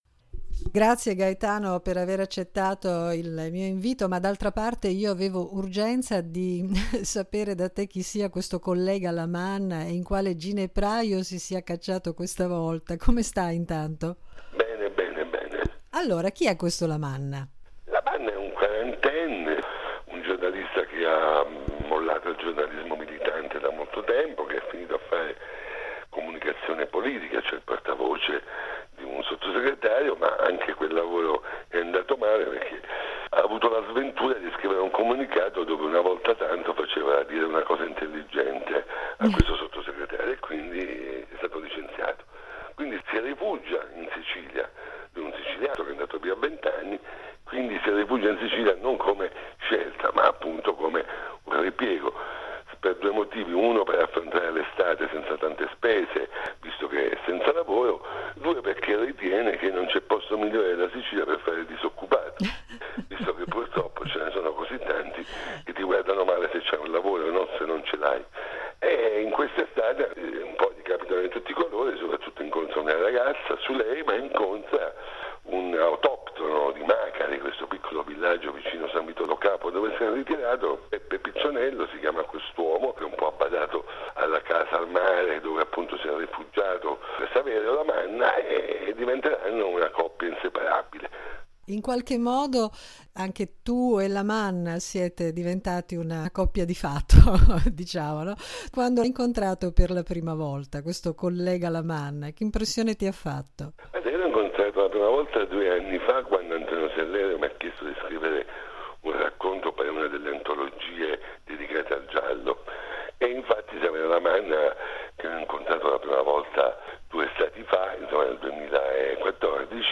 “La fabbrica delle stelle”: chiacchierata con Gaetano Savatteri